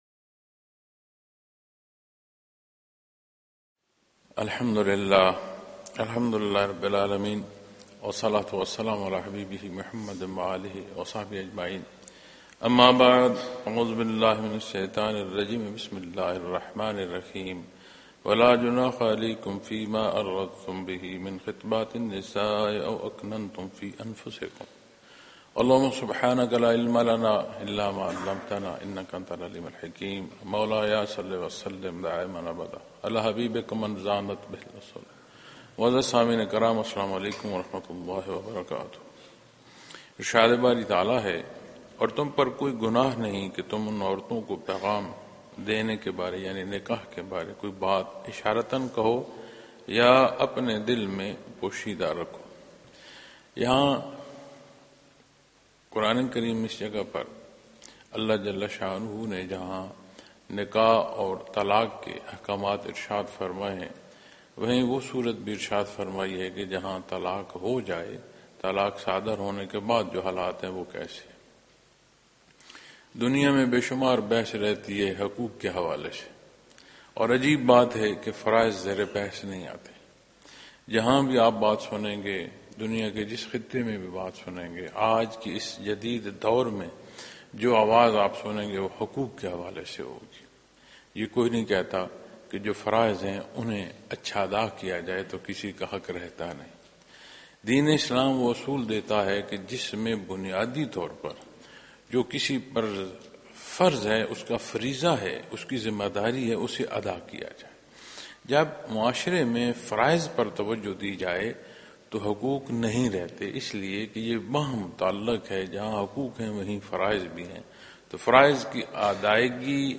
Munara, Chakwal, Pakistan